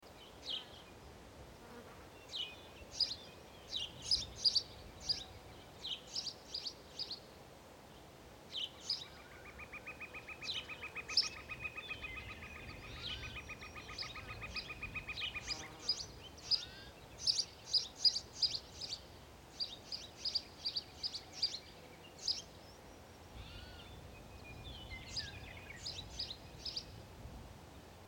جلوه های صوتی
دانلود صدای جنگل 6 از ساعد نیوز با لینک مستقیم و کیفیت بالا